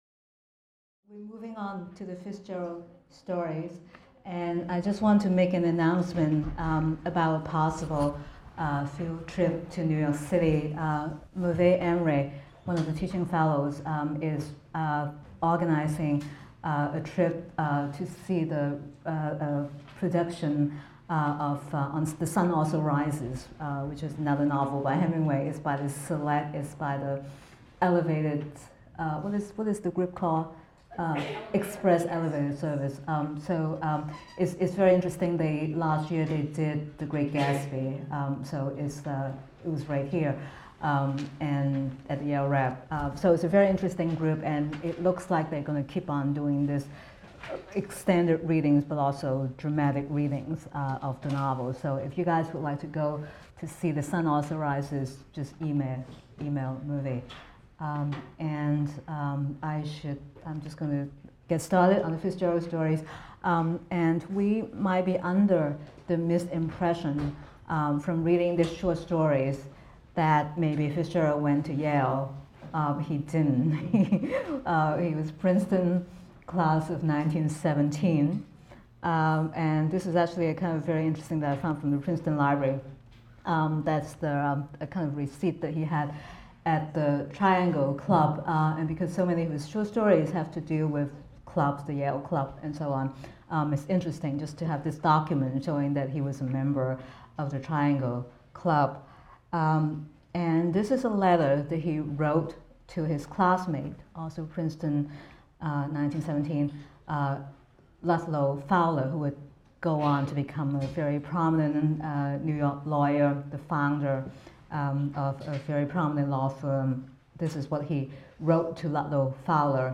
AMST 246 - Lecture 12 - Fitzgerald’s Short Stories | Open Yale Courses